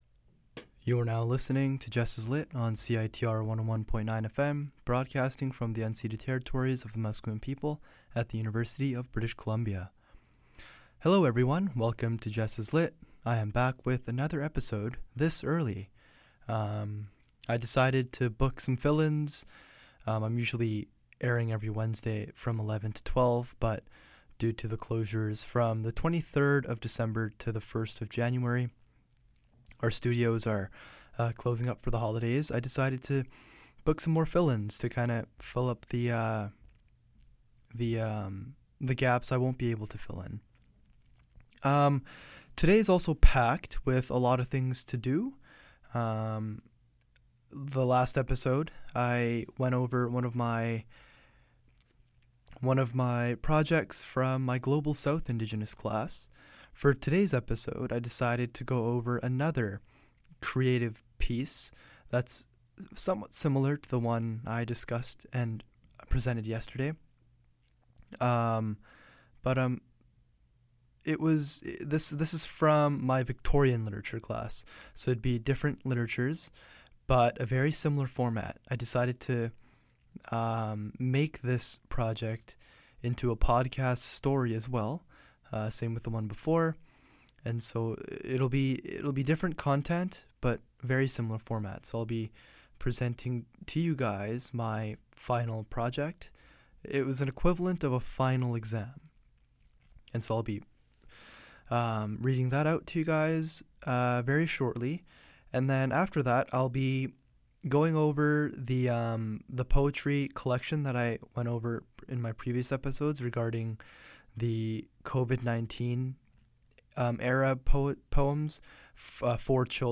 In this episode I will read to y'all my final creative project from my Victorian literature class. Consider the title of this episode and tune in to fully understand what it means:) I will also, after presenting my creative work, continue reading several poems from the children’s poetry book about Covid-19 by Funmilayo Adesanya-Davies.